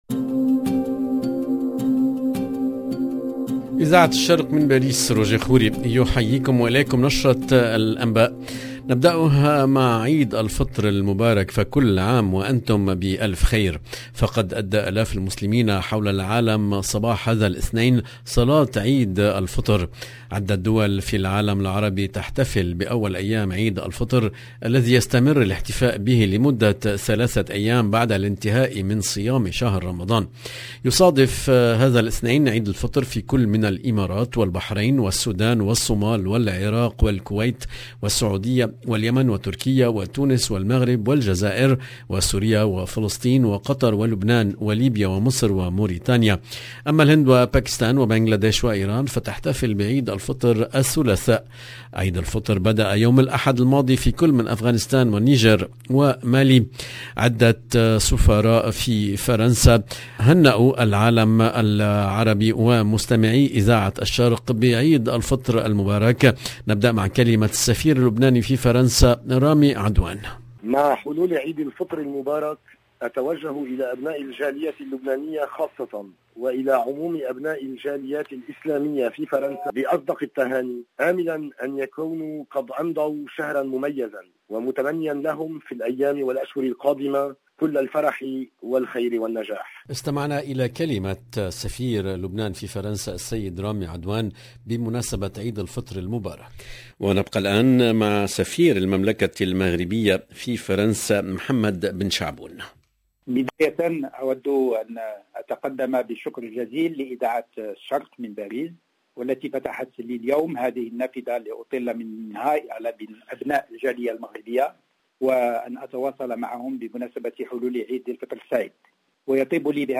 EDITION DU JOURNAL DU SOIR EN LANGUE ARABE DU 2/5/2022